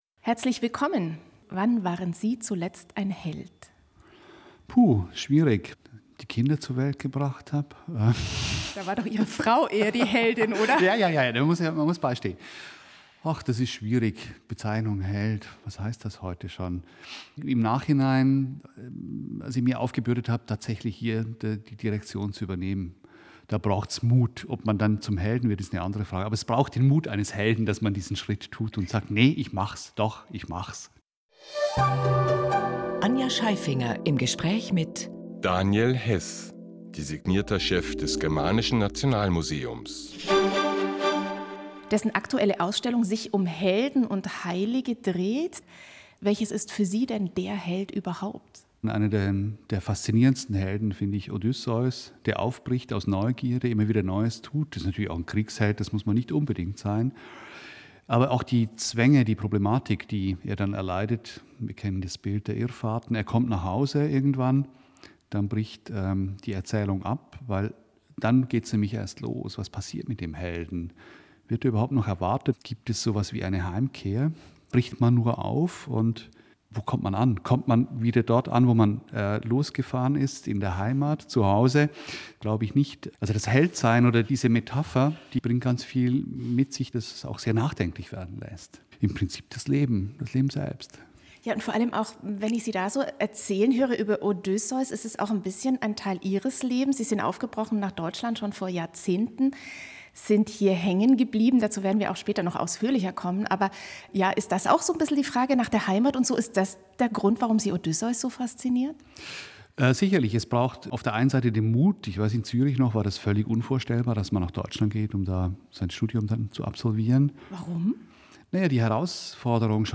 Der schöne Grund für diesen Blogbeitrag ist sein absolut hörenswerte Interview, das bei uns auf eine Renaissance für die Wertschätzung des (Kunst-)Handwerks hoffen lässt.